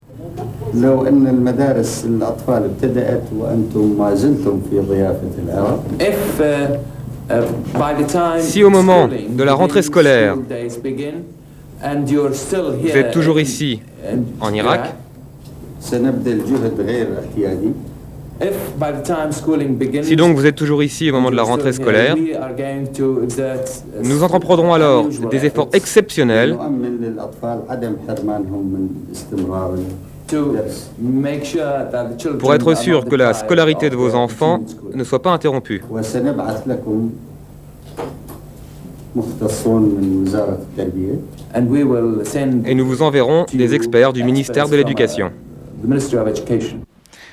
Mitterrand, François : Hommage à Pierre Bérégovoy (Podcast)